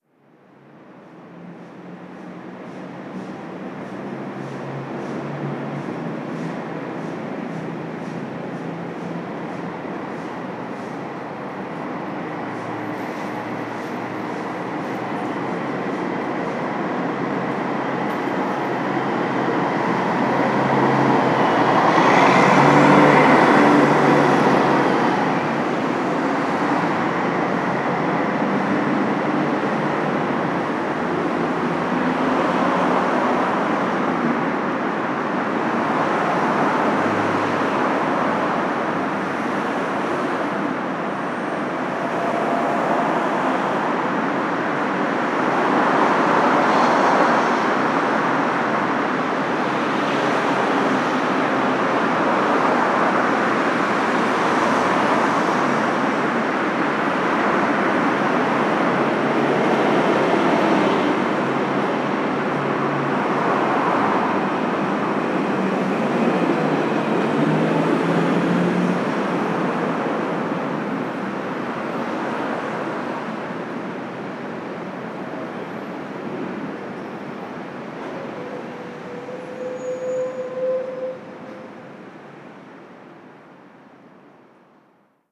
Ambiente de la Gran Vía, Madrid
tráfico
barullo
motor
Sonidos: Transportes
Sonidos: Ciudad